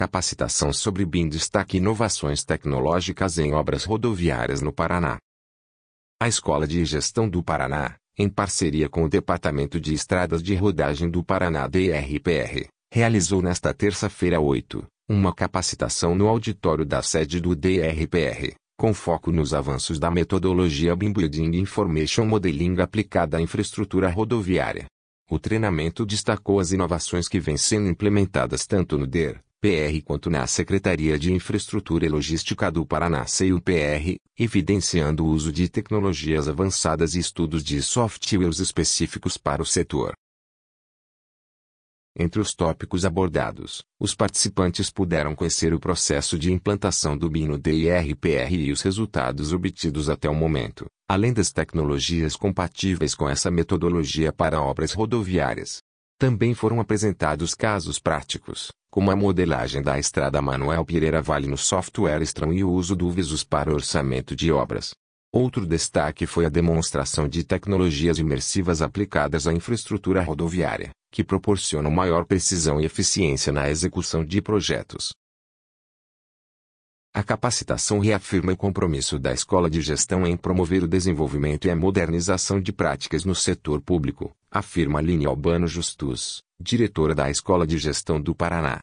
audionoticia_capacitacao_em_bim.mp3